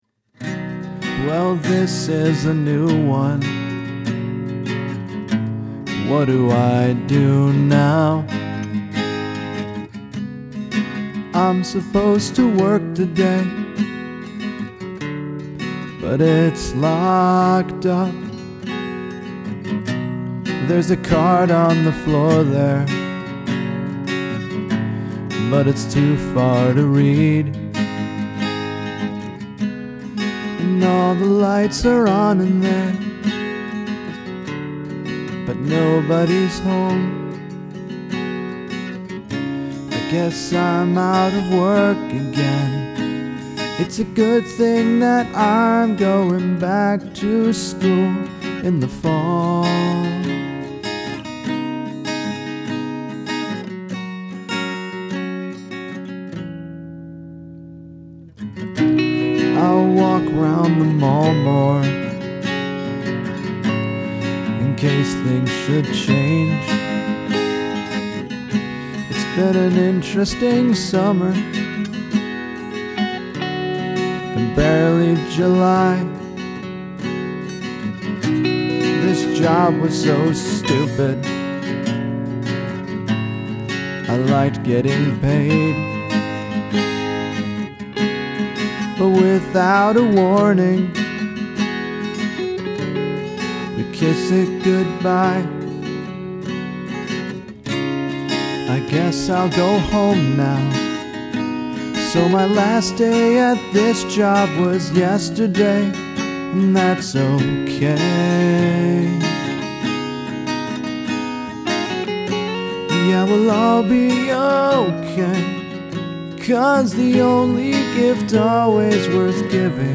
But, I used an electric guitar here as the additional instrument because I don’t have a piano or a string quartet at my disposal.